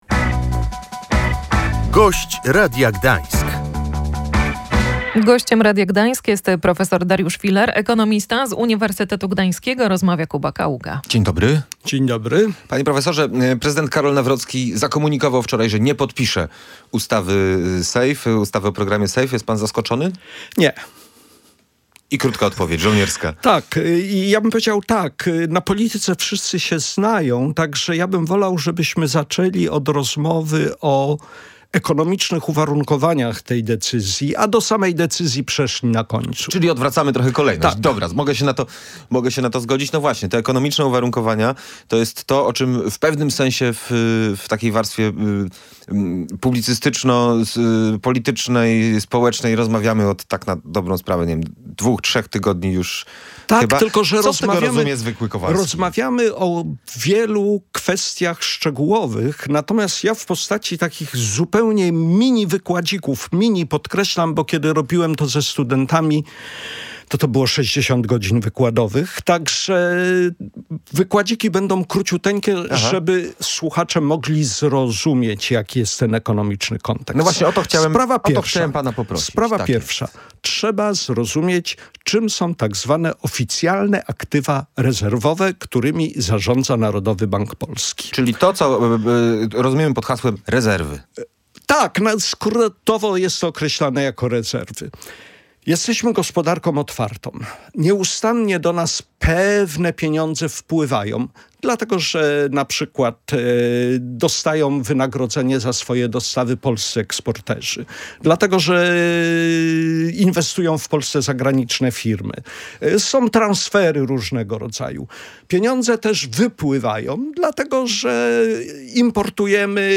Zawetowanie przez prezydenta ustawy wdrażającej europejski program SAFE nie jest zaskoczeniem – mówił Gość Dnia Radia Gdańsk ekonomista prof. Dariusz Filar.